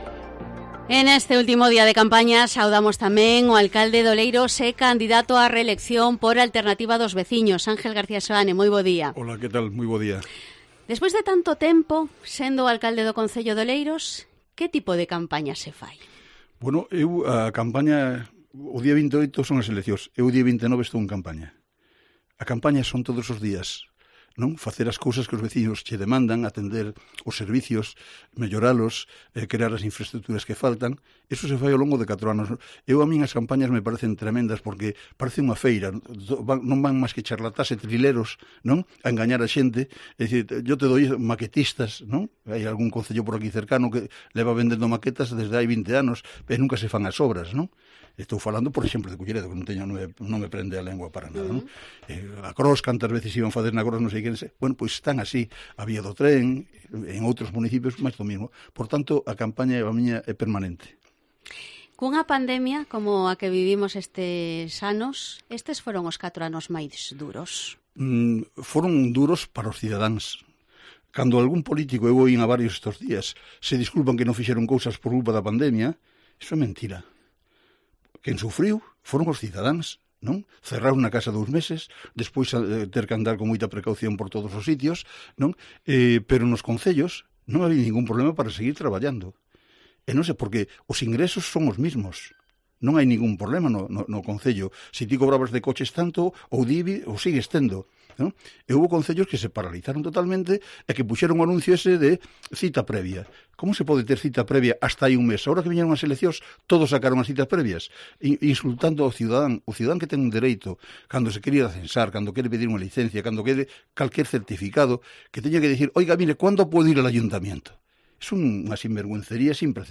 Entrevista al candidato a la reelección a la alcaldía de Oleiros, Ángel García Seoane
AUDIO: Entrevista al candidato a la reelección a la alcaldía de Oleiros, Ángel García Seoane (Alternativa dos Veciños)